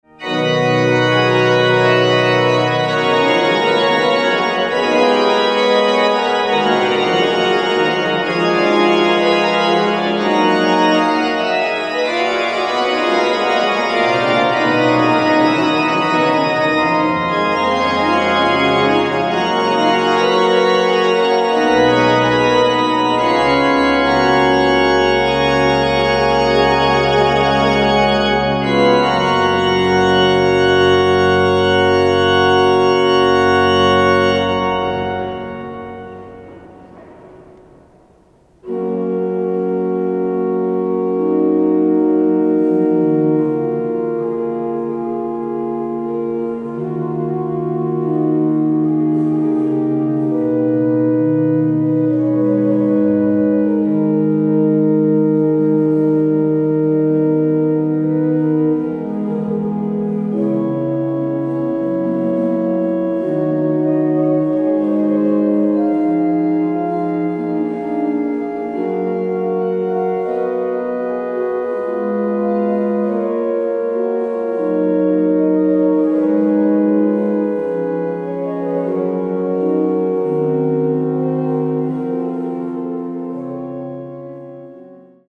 České Budějovice – klášterní kostel Panny Marie
Hudební ukázka: (stáhnout mp3)
Manuál:  C,D,E – c³ = 47t.
Pedál:  C,D,E,F,G,A – c¹ = 21t.
Ladění: a - 464Hz / 20°C, Valotti